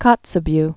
(kŏtsə-by)